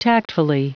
Prononciation du mot tactfully en anglais (fichier audio)
Prononciation du mot : tactfully